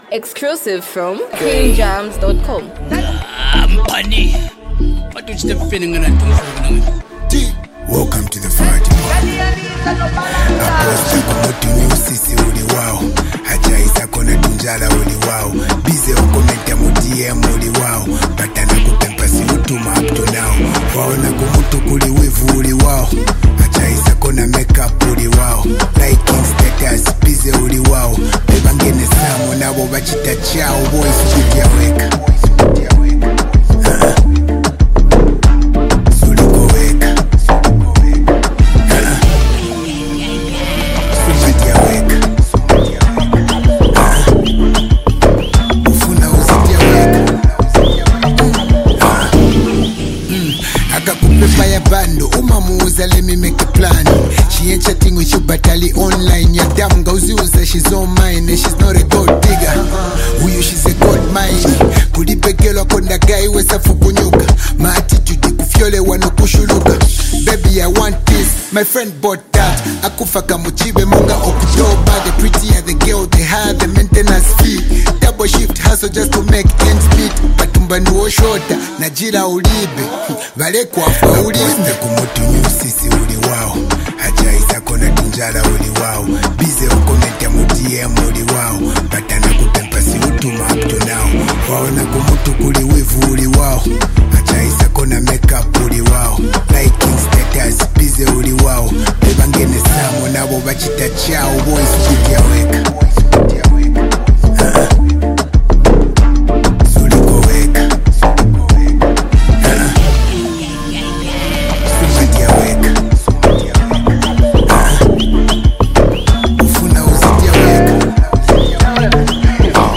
street vibes, and a powerful hook with unique rap flow